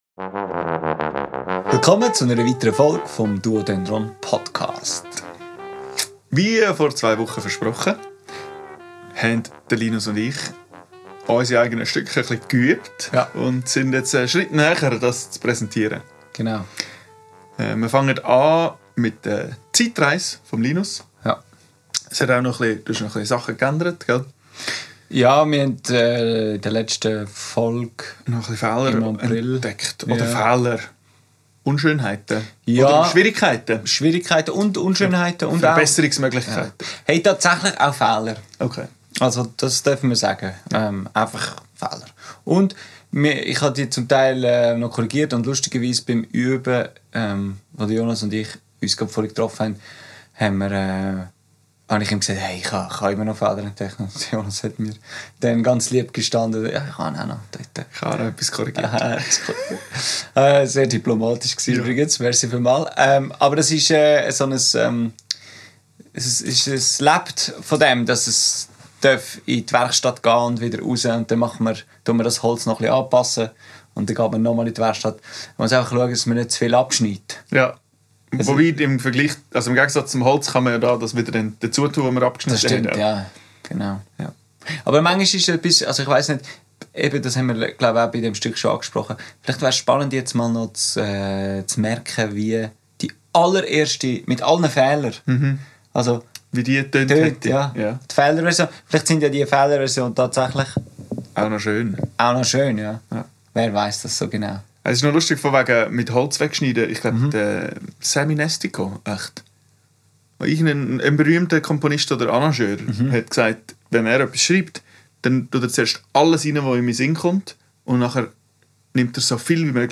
Aufgenommen am 17.04.2025 im Atelier